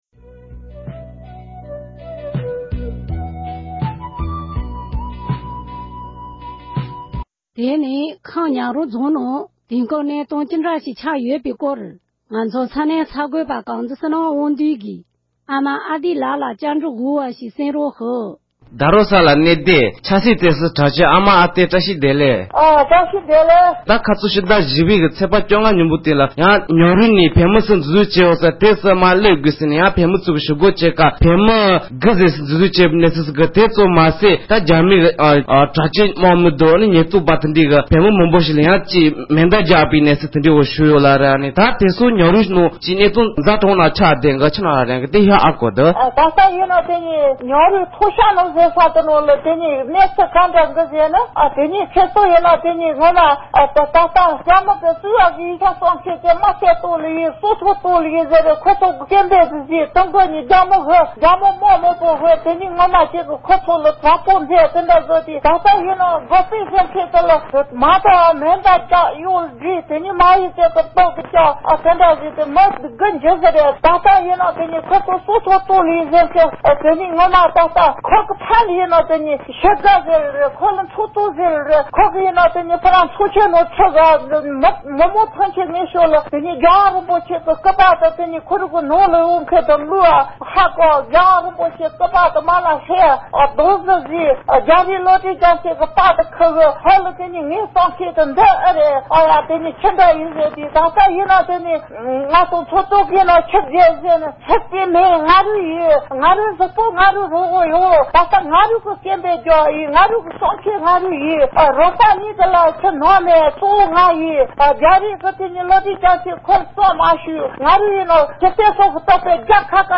བཀའ་འདྲི་ཞུས་ཞིག